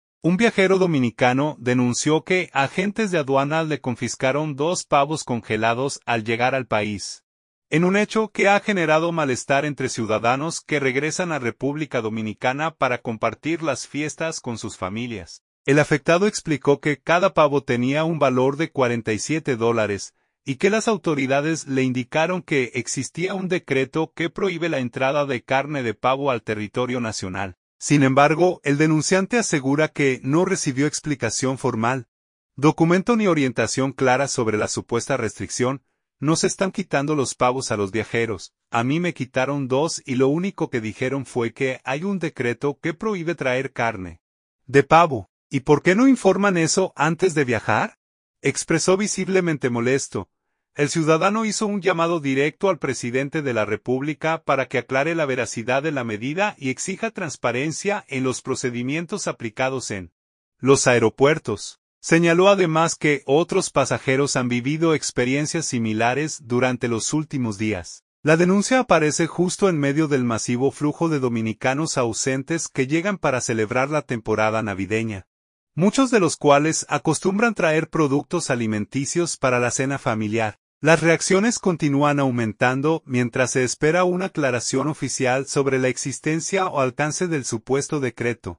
“Nos están quitando los pavos a los viajeros. A mí me quitaron dos y lo único que dijeron fue que hay un decreto que prohíbe traer carne de pavo. ¿Y por qué no informan eso antes de viajar?”, expresó visiblemente molesto.